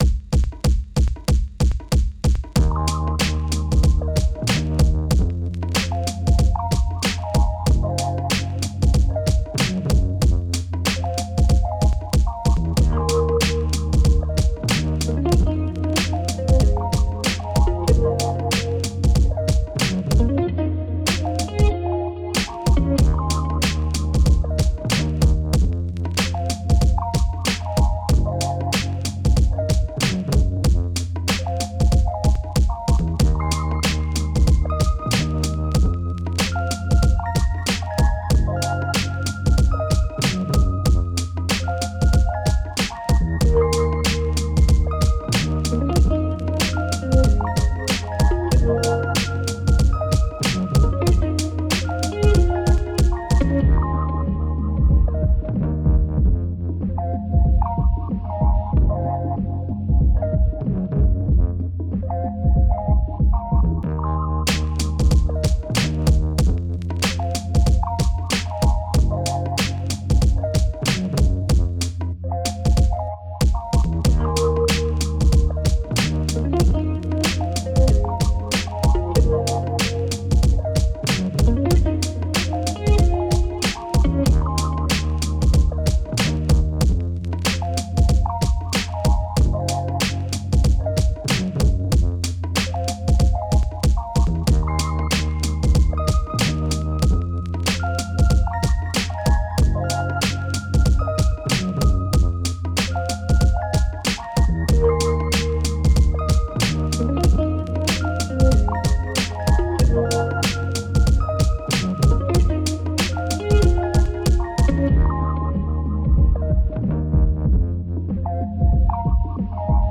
R&B, Hip Hop, 80s, 90s
D#minor